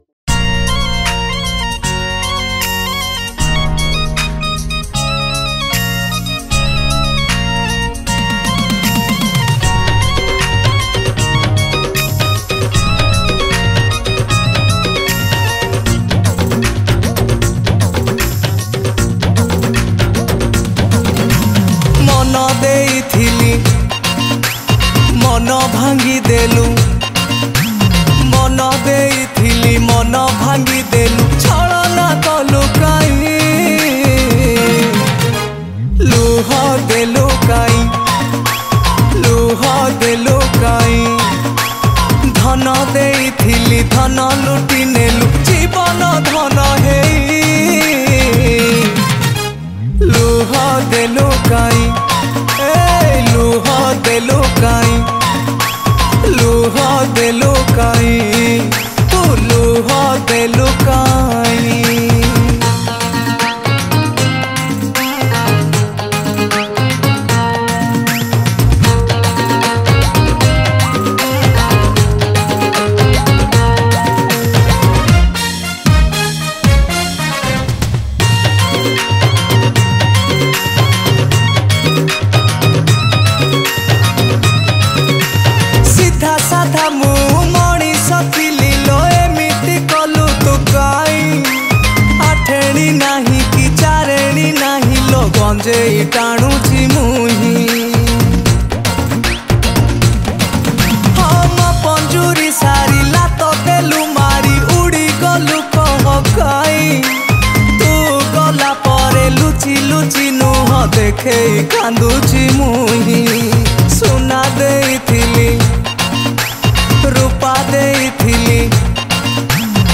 New Odia Album Songs